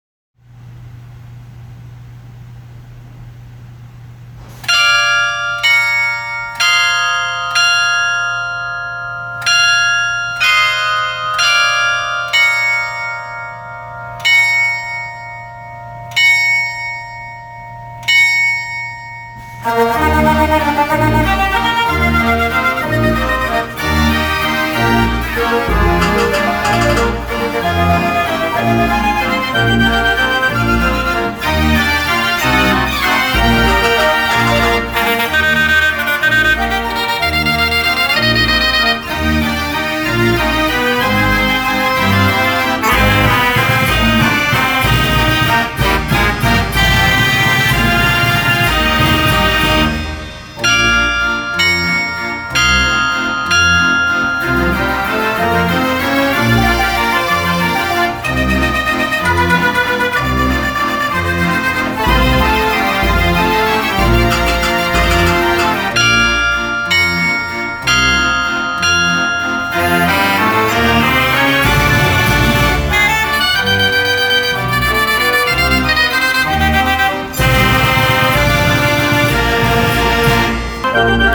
Gaudin 125-Key Dance Organ - Stahls Automotive Collection
In the 1920s, they shifted their emphasis to elaborate dance organs.
The musical scale was extended to include a full rank of massive bronze carillon bells, the largest of which weighs about 600 pounds!